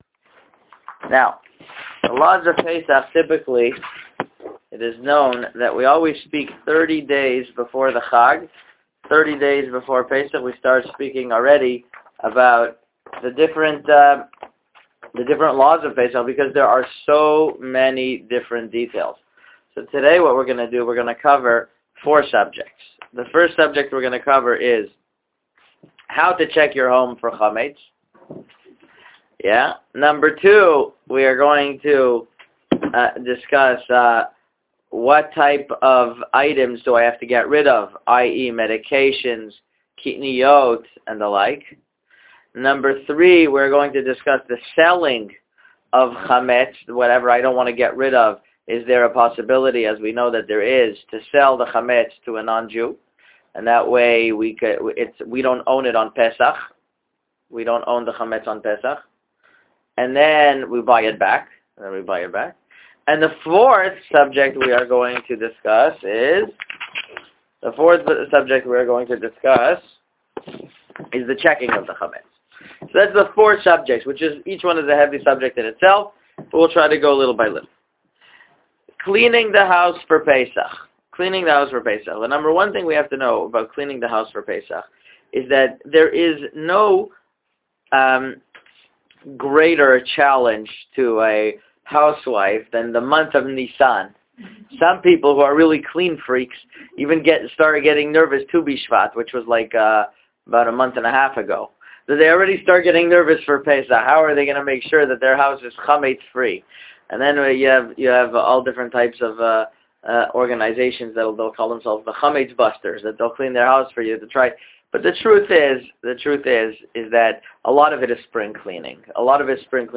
A pre-Pessah audio Shiur